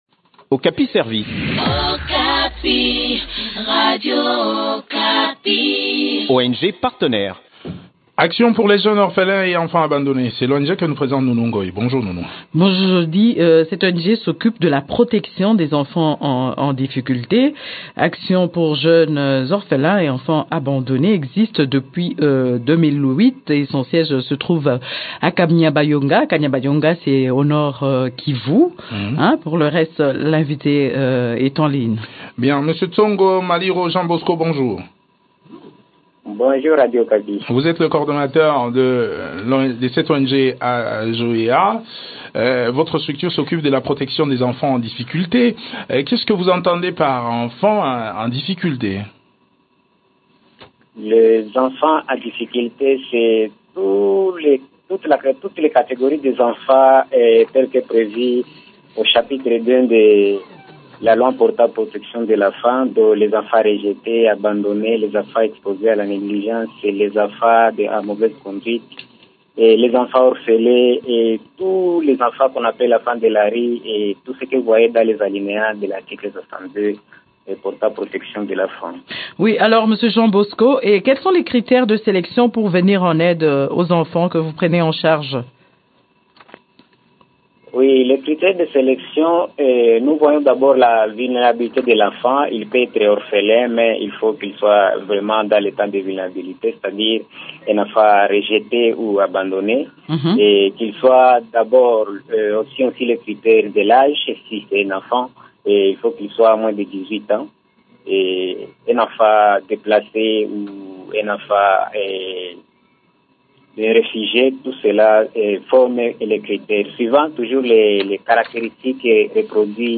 font le point des activités de cette ONG avec